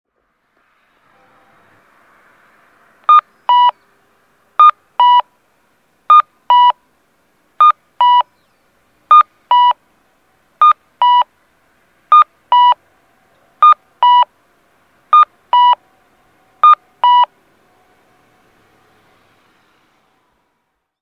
JR幡生機関区先(山口県下関市)の音響信号を紹介しています。